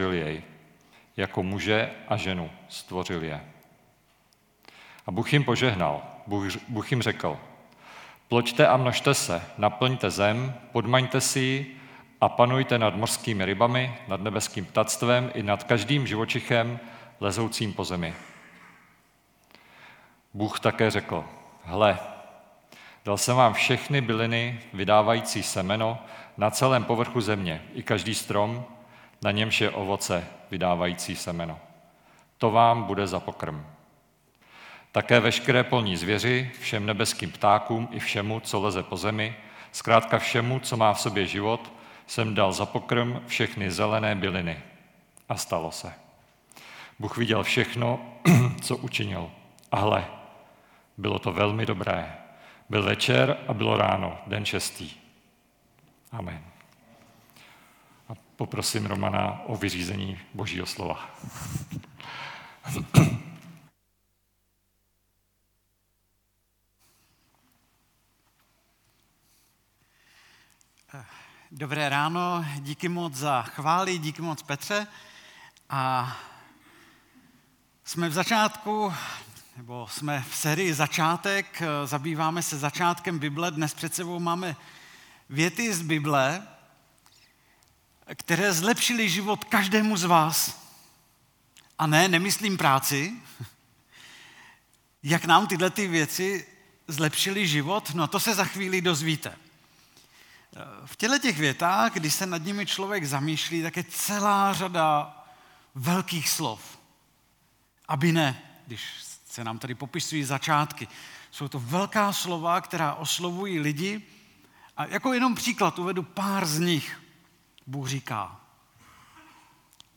Kategorie: Nedělní bohoslužby